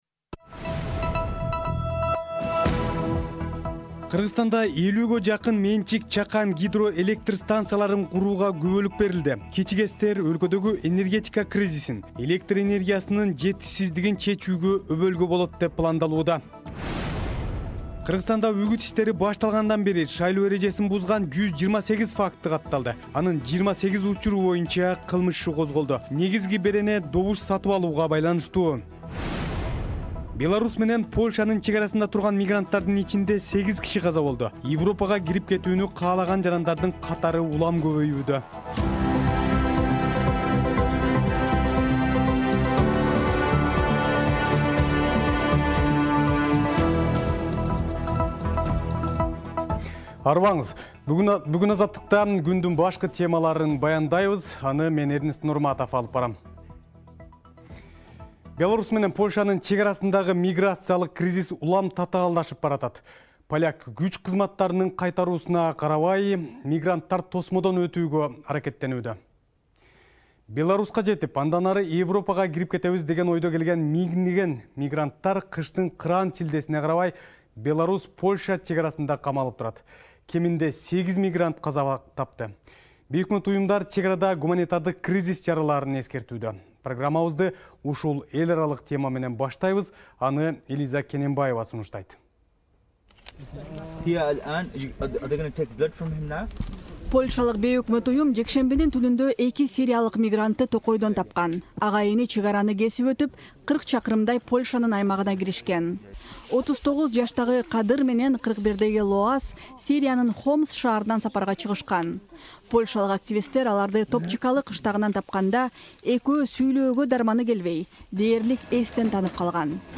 Жаңылыктар | 15.11.2021 | Президент Жапаров элге көмүр 3000 сомдон сатыларын айтты